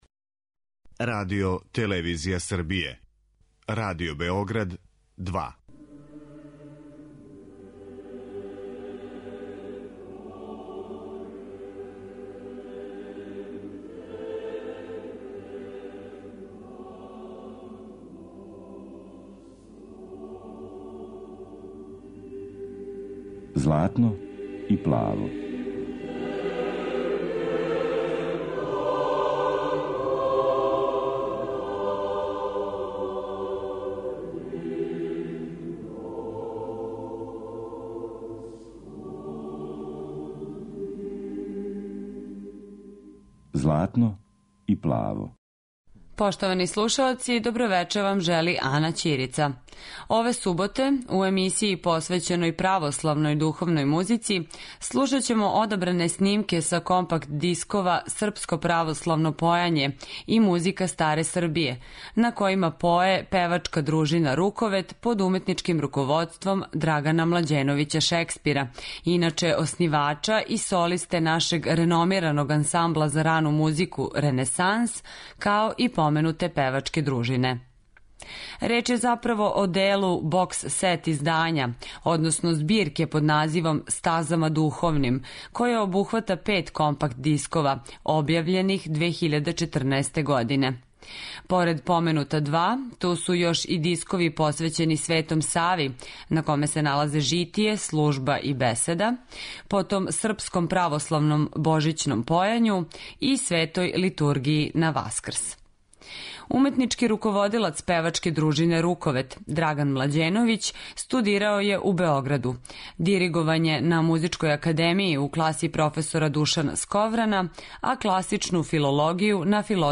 Емисија православне духовне музике